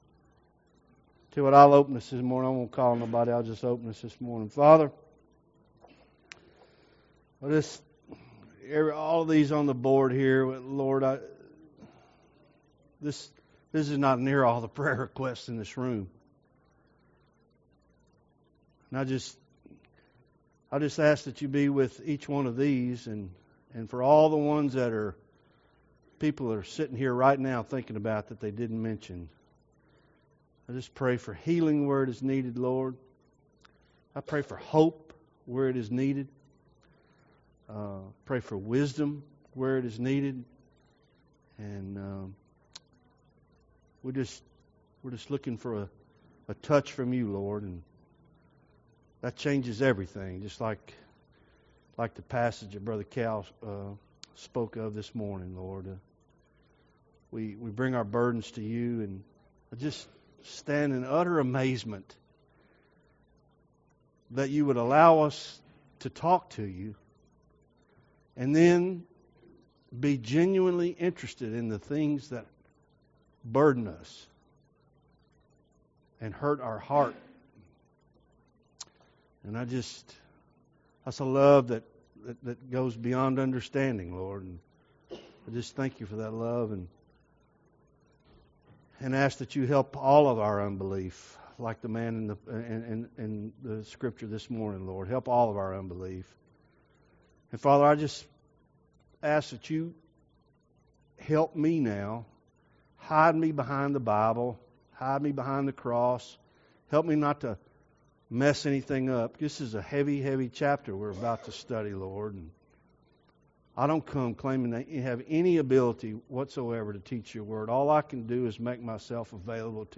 Sunday School Ephesians 4